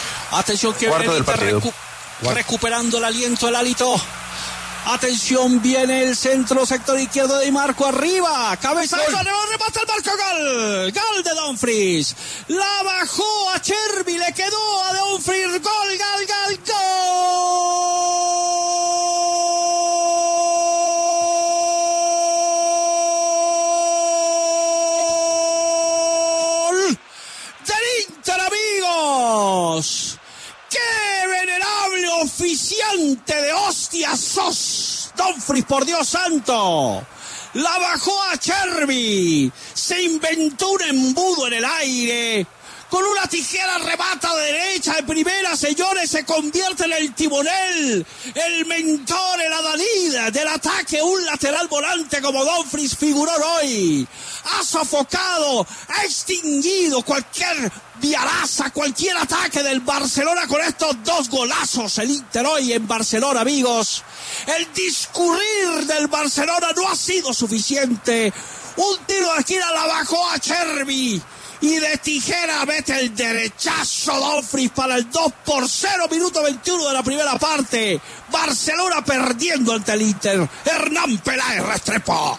“Que venerable oficiante de ostias sos”: Narración de Martín De Francisco del gol de Dumfries
Así narró el gol del Inter Martín De Francisco: